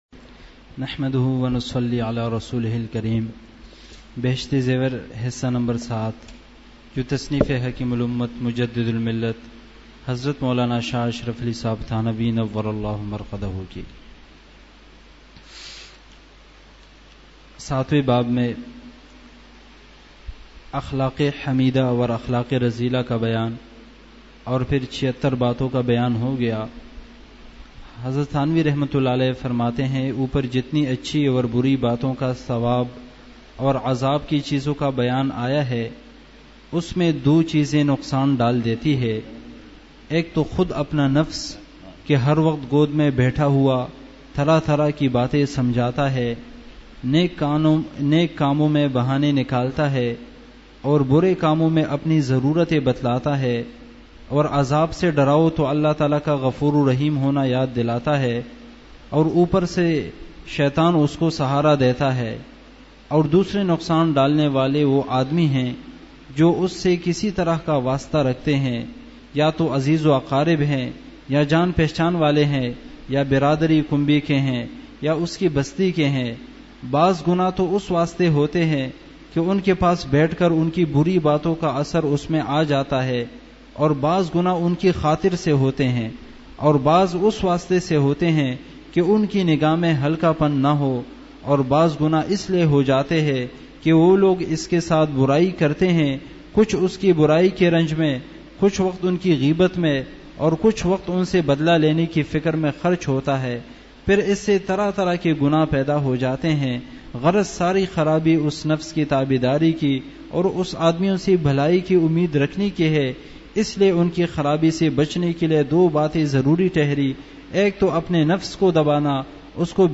مجلس ۶ جولائی ۲۰۱۹ء بعد عشاء : گناہ کے دنیا و آخرت کے نقصانات !